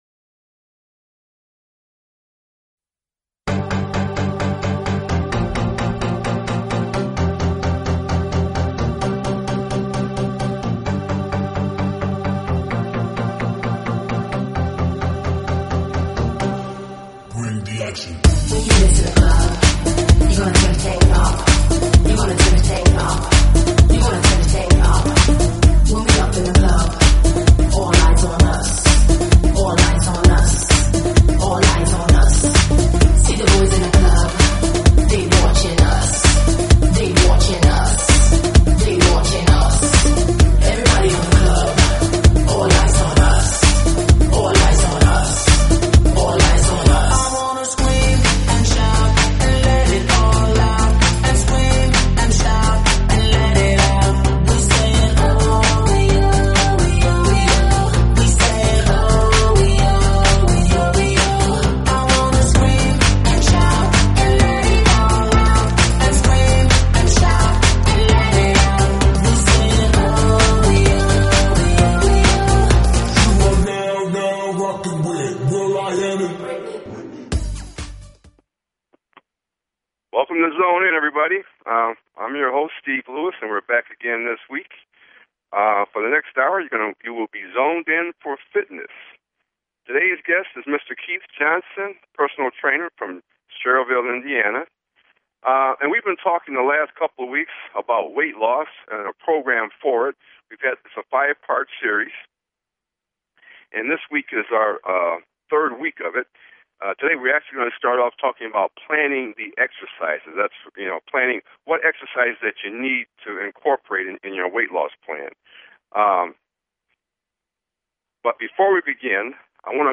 Talk Show Episode, Audio Podcast, Zone In!